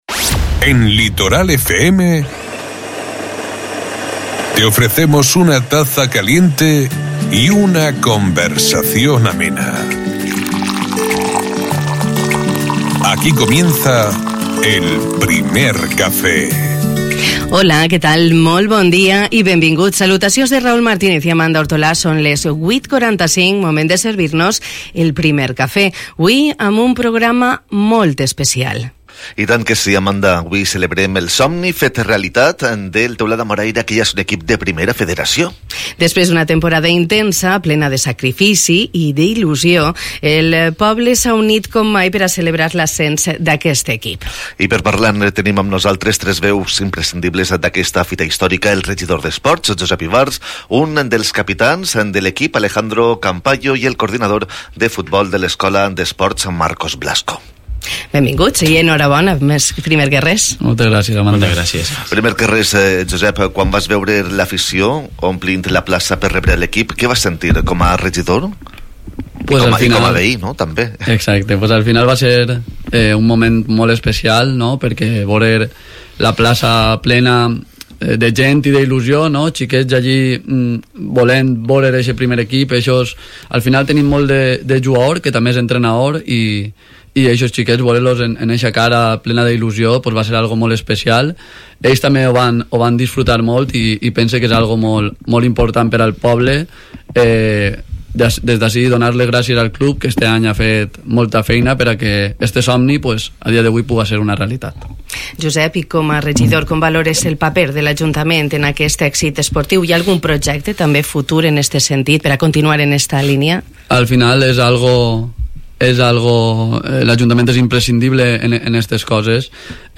Una charla distendida en la que hemos conocido de cerca las claves de una temporada memorable, llena de esfuerzo, entrega y pasión por el fútbol.